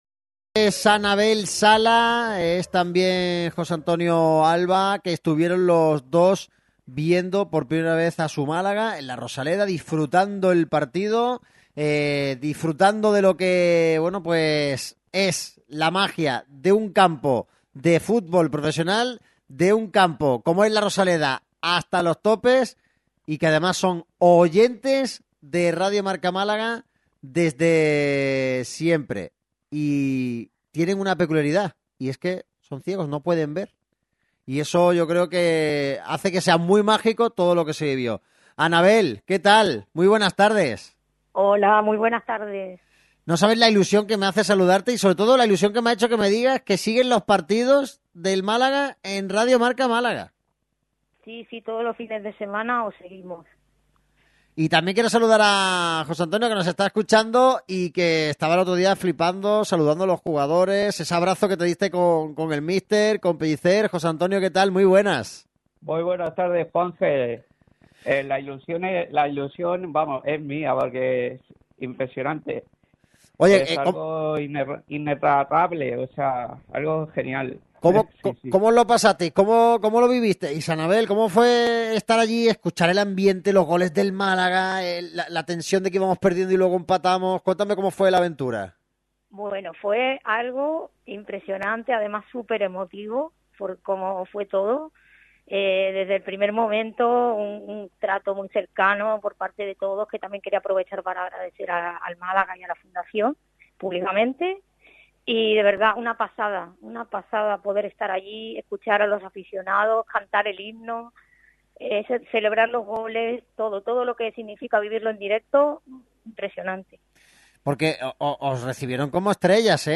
Ambos, este viernes, pasaron en una entrevista muy especial en la radio del deporte.